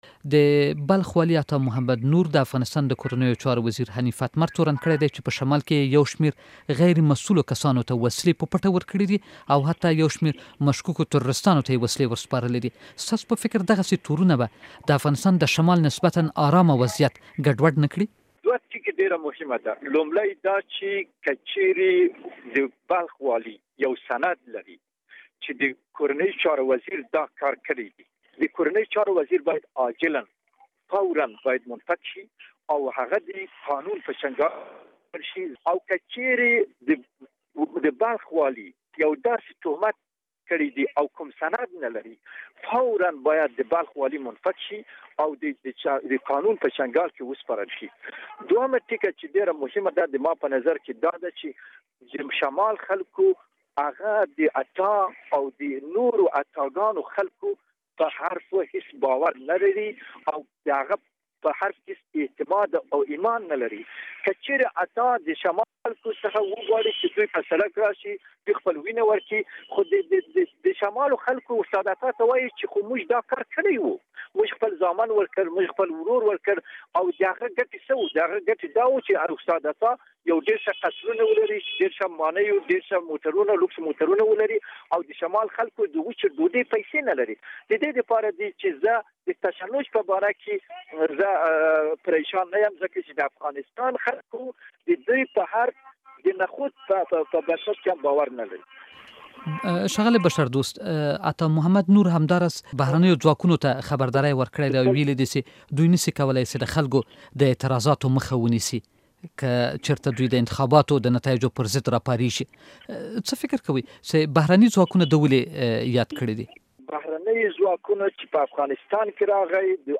له رمضان بشردوست سره مرکه واورﺉ